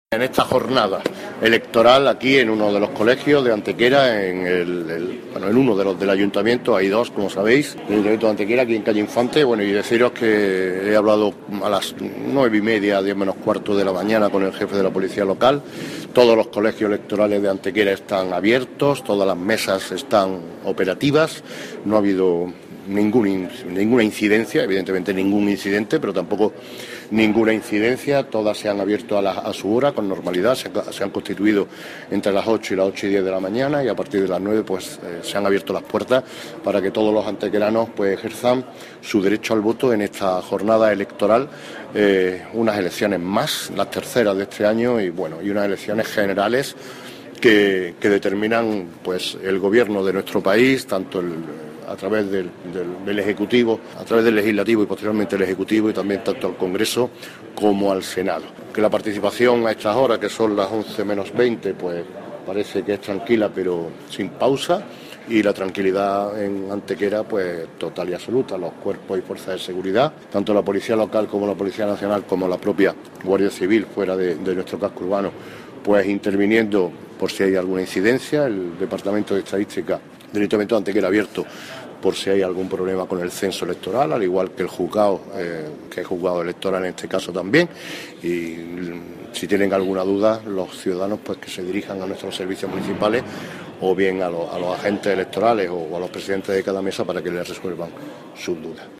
Cortes de voz M. Barón 775.32 kb Formato: mp3